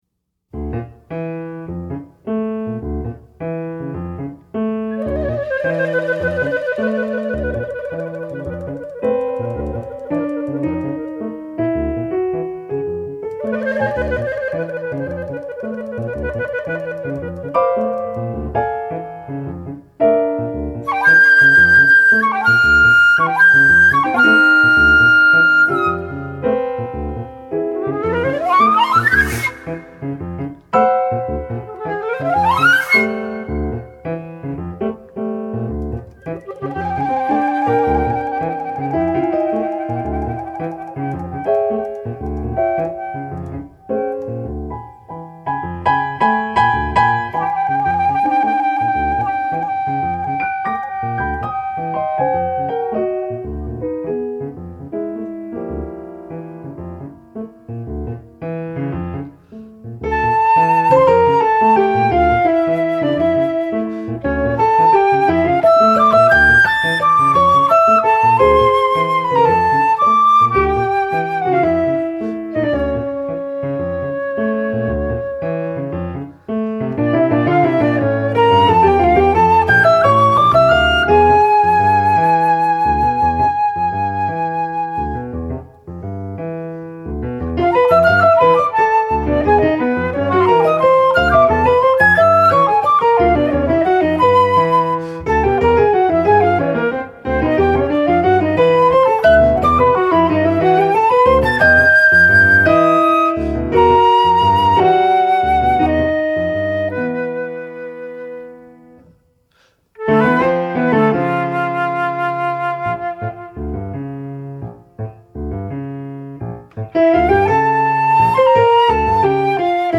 piano, drums
flute, saxophone, shakuhachi